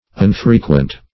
Unfrequent \Un*fre"quent\, a. [Pref. un- not + frequent.]